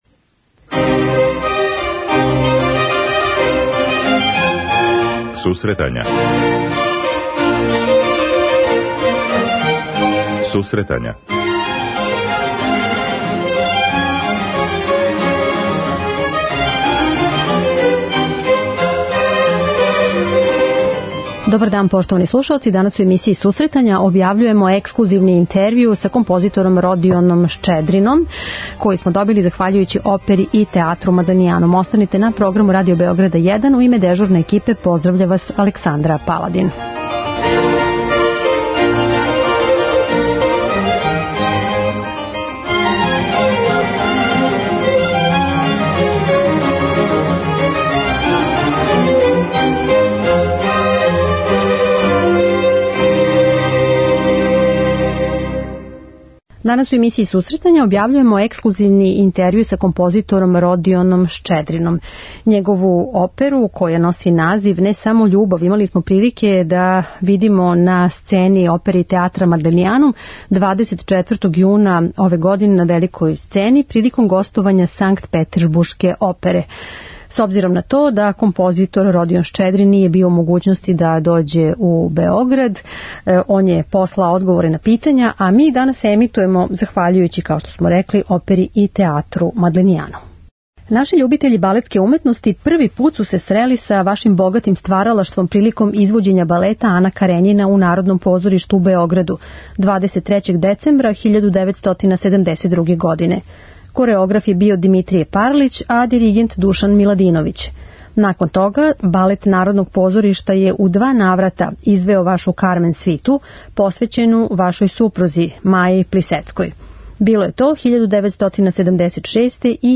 У емисији емитујемо ексклузивни интервју са композитором Родионом Шчедрином, чија је опера 'Не само љубав' изведена на самом крају сезоне у Опере и театра Мадленианум, приликом гостовања Санкпетесбуршке опере.